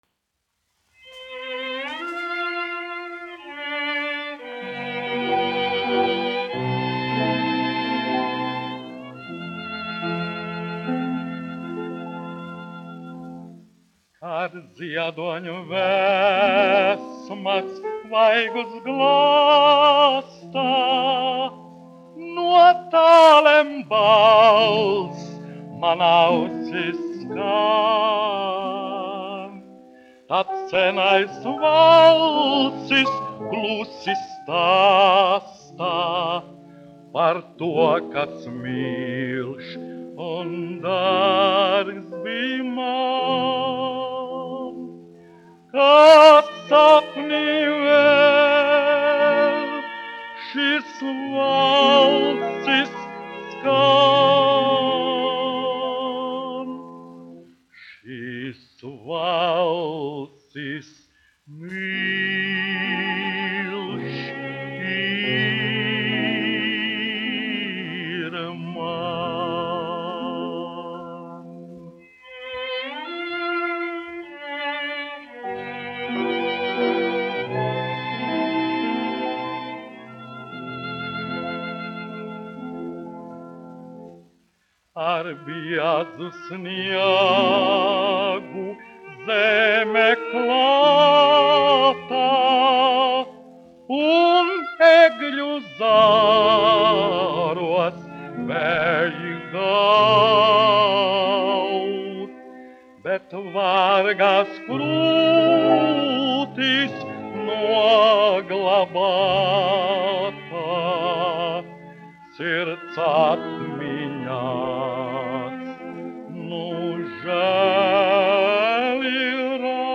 1 skpl. : analogs, 78 apgr/min, mono ; 25 cm
Romances (mūzika)
Skaņuplate
Latvijas vēsturiskie šellaka skaņuplašu ieraksti (Kolekcija)